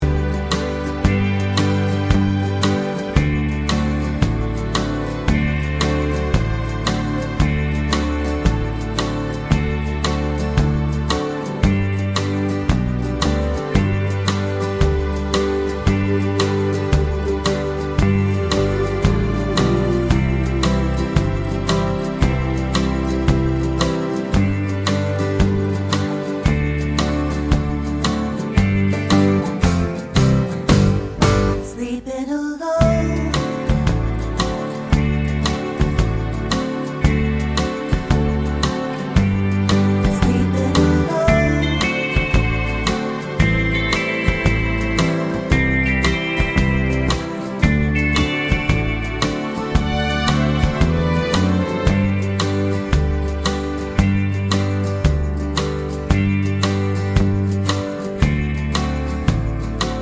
no Backing Vocals Country (Male) 3:29 Buy £1.50